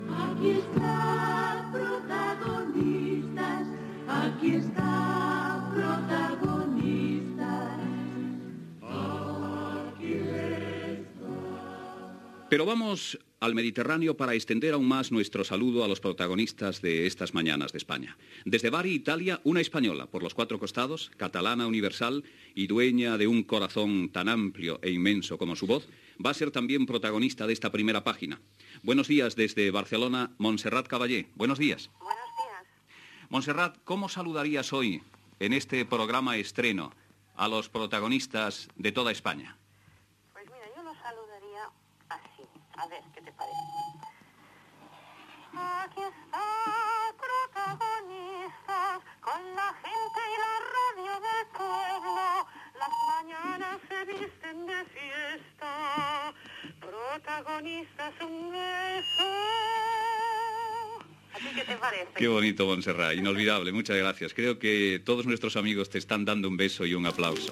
Indicatiu cantat del programa, salutació cantada de la soprano Montserrat Caballé, des de Bari, en la primera edició del programa a la ràdio privada.
Info-entreteniment